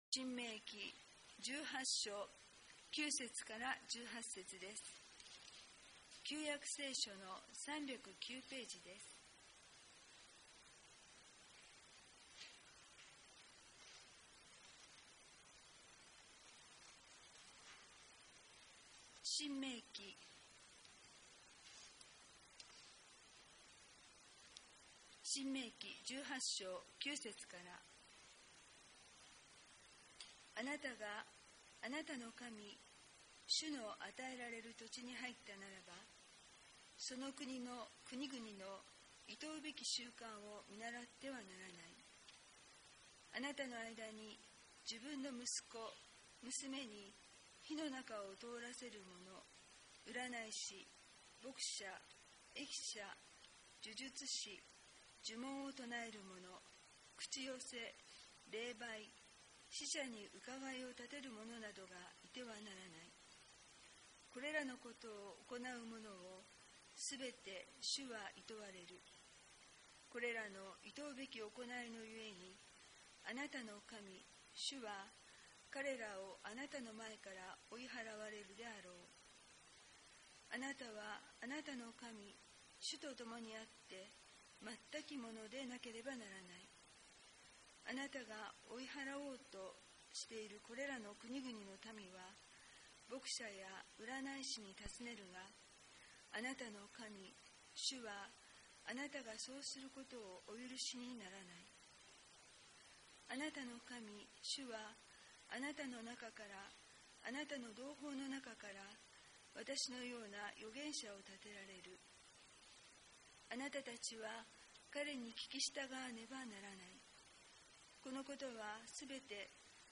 2026年1月1日（木）、 早朝、愛宕町教会では恒例の「元旦礼拝」を行いました。
説教